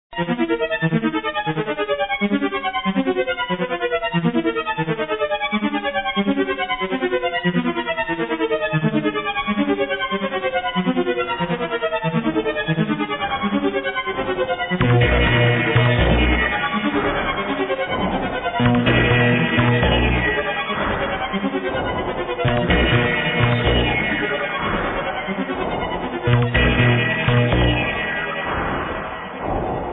muzyka elektroniczna, ambient